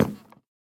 Minecraft Version Minecraft Version latest Latest Release | Latest Snapshot latest / assets / minecraft / sounds / block / hanging_sign / step1.ogg Compare With Compare With Latest Release | Latest Snapshot
step1.ogg